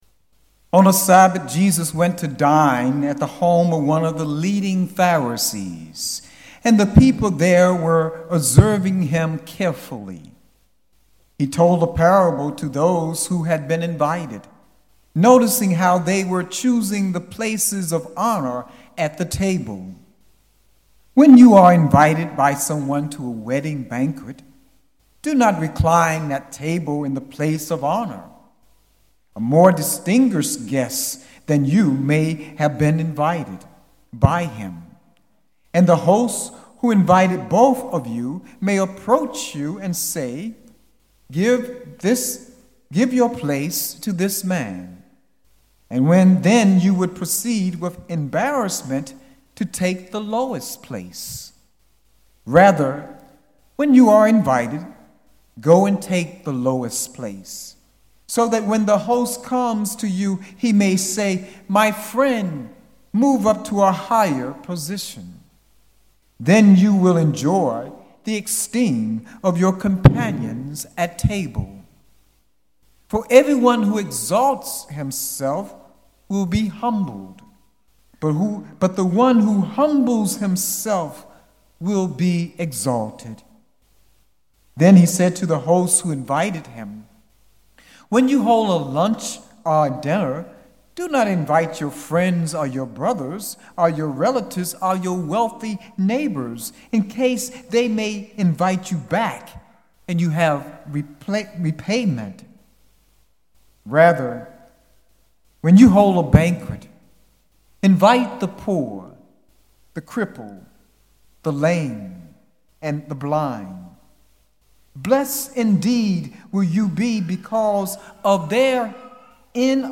Sermons Archive - Our Lady of Lourdes